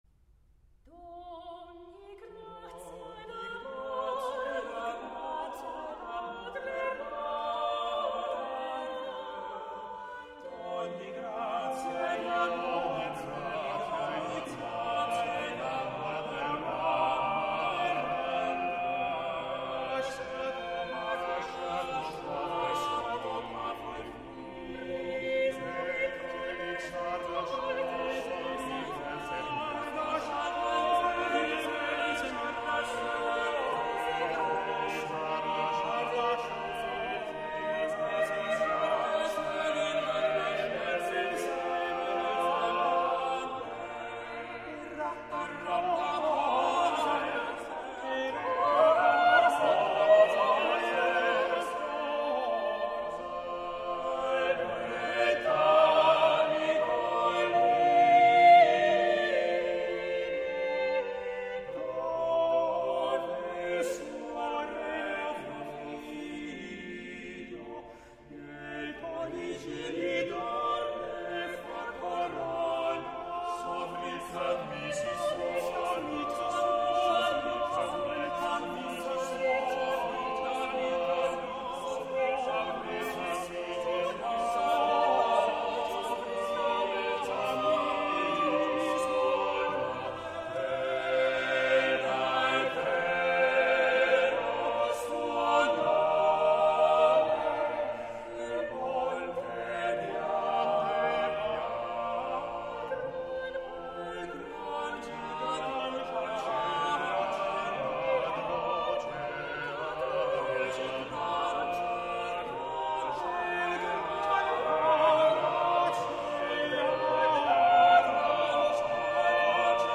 This is an a capella (unaccompanied) piece written in 40 parts split into five distinct choirs each made up of 8 parts.
The 40 parts all blend together in a marvellous balance of harmony, counterpoint and dynamic.